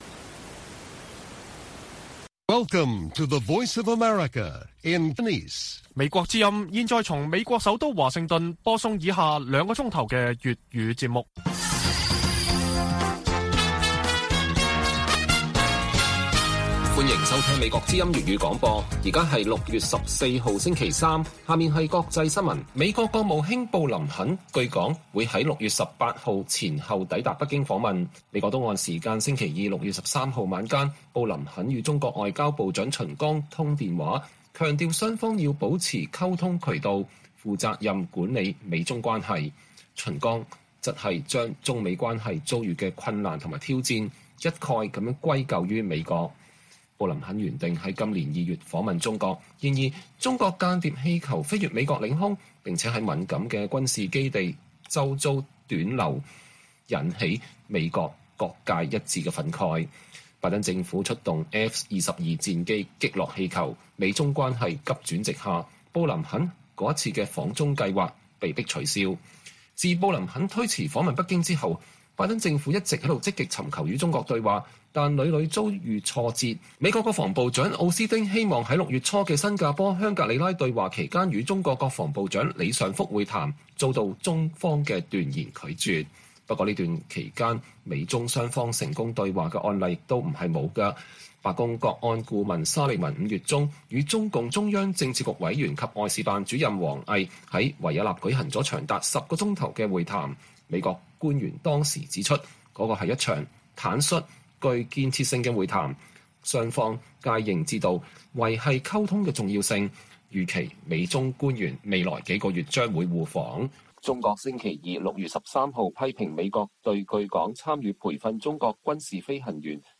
粵語新聞 晚上9-10點: 布林肯計劃訪華前與秦剛通電話